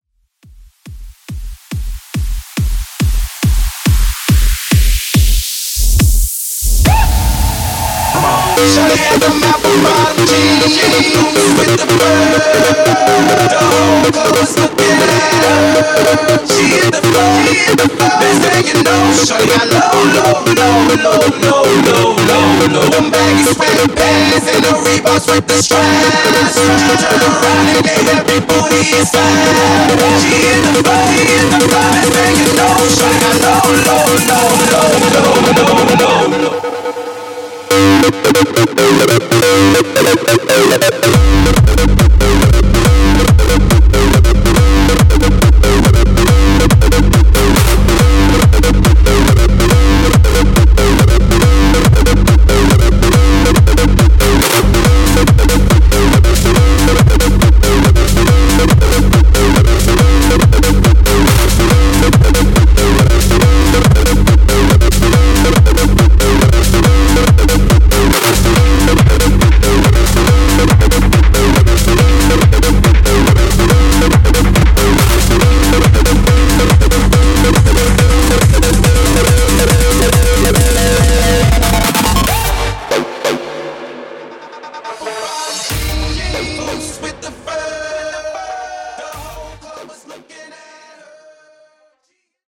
Genres: 90's , RE-DRUM
Clean BPM: 100 Time